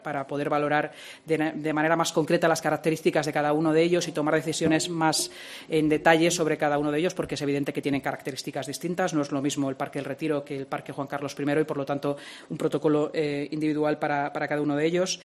Inmaculada Sanz, Vicealcaldesa de Madrid